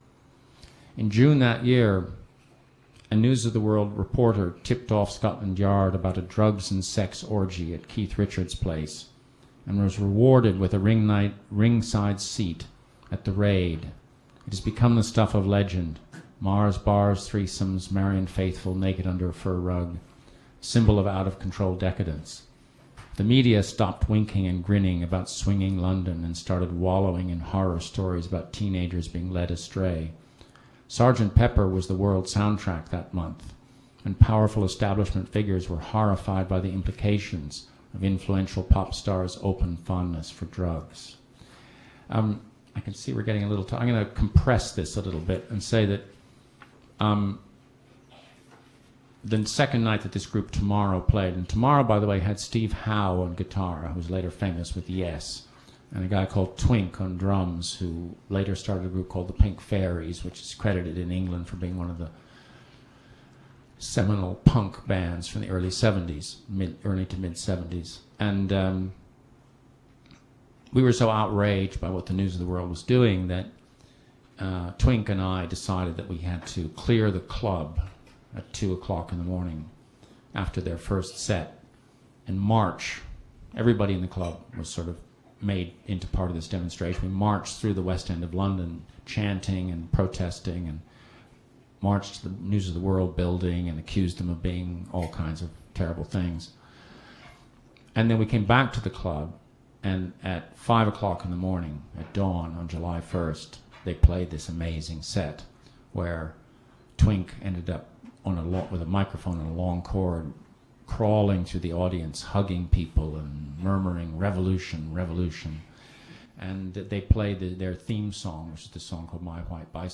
The premise of the book, which we here see Joe reading from at the Soho McNally Robinson bookstore in NYC, is that the 60's started in 1956, when the arrival of Dick Clark's sanitized version of rock'n'roll created an underground consciousness which clung to its gritty roots of blues and folk for sustenance; peaked on July 1 1967, with a cathartic performance by the group Tomorrow at UFO; and petered out in 1973, as oil embargoes, inflation, etc starved out the last of it's hedonistic anarchistic lifeblood.